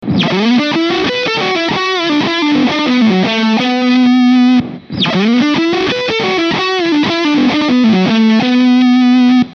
In each file I recorded the same phrase with a looper: fiirst with the Original Big Muff inserted in a loop just before the Stack and after with the Kemper Stomps. I used HiWatt profiles here, but same results I obtained with Marshall and Fender Amps…
Here some Audio Tests (in each phrase is played first the Original Muff and then the Kemper Emulation):
TEST-BigMuff-Ram-11.mp3